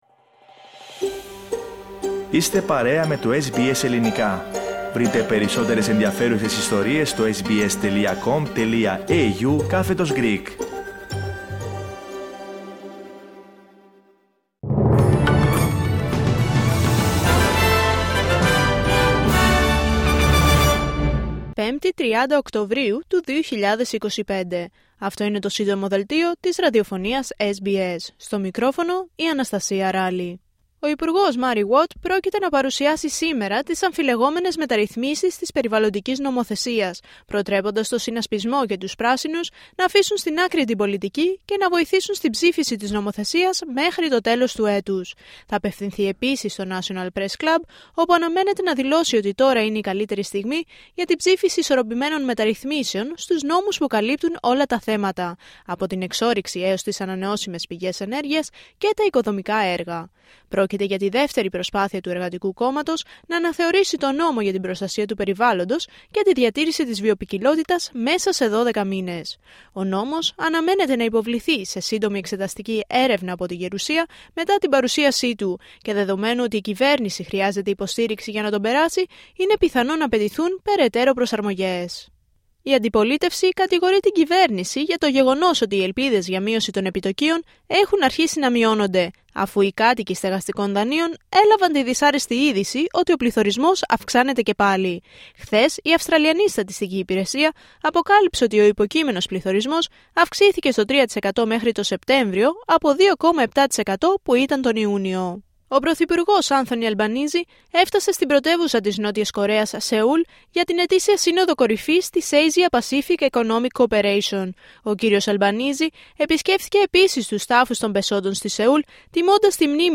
H επικαιρότητα έως αυτή την ώρα στην Αυστραλία, την Ελλάδα, την Κύπρο και τον κόσμο στο Σύντομο Δελτίο Ειδήσεων της Πέμπτης 30 Οκτωβρίου 2025.